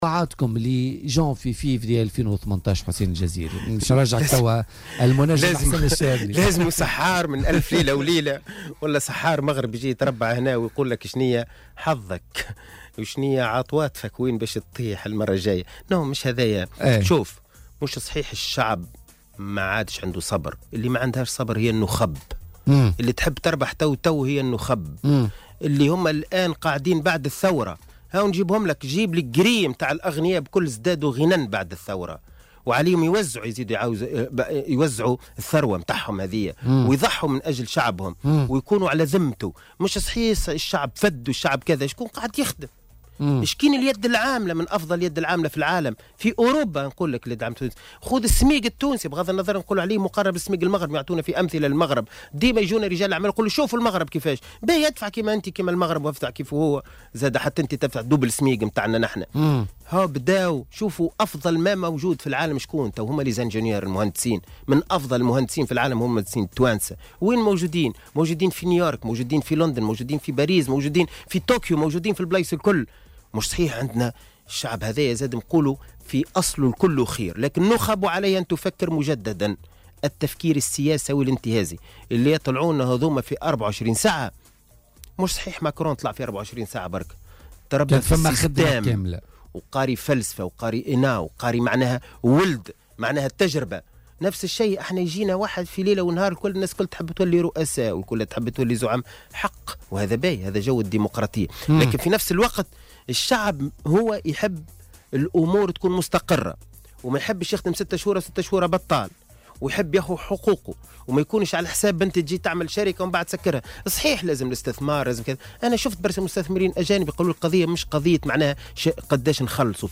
أكد القيادي في حركة النهضة حسين الجزيري ضيف بولتيكا اليوم الخميس 30 نوفمبر 2017 أن يوسف الشاهد ينتمي للجيل الجديد وللجمهورية الثانية التي تؤسس للمستقبل وتقطع مع التصنيفات الإيديولوجية وتوحد بين التونسيين.